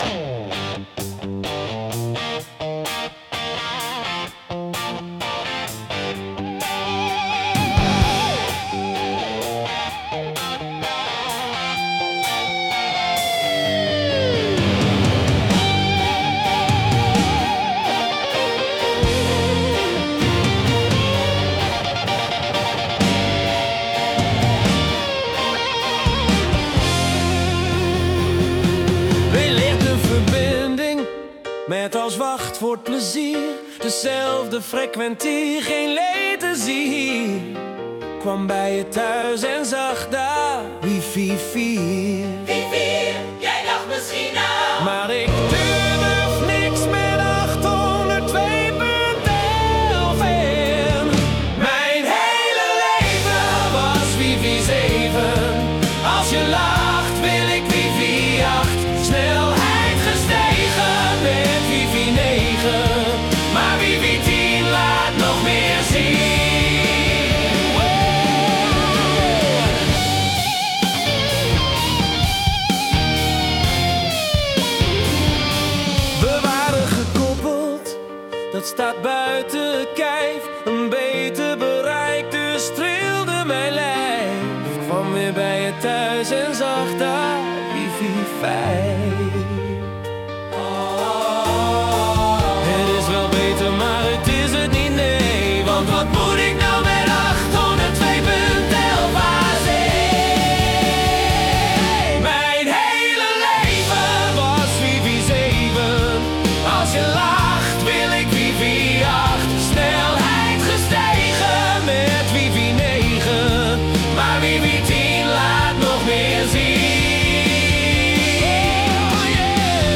Classic rock
Begon oke maar vanaf dat eerste refrein echt leuk.